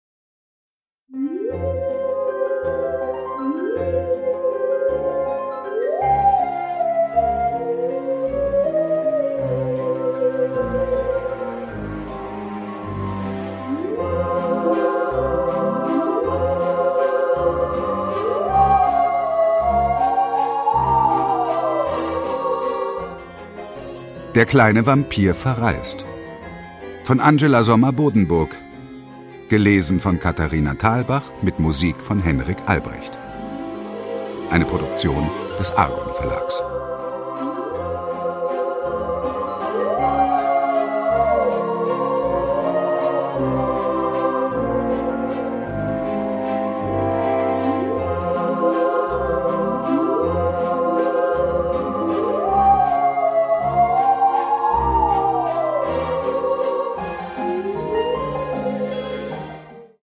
Produkttyp: Hörbuch-Download
Gelesen von: Katharina Thalbach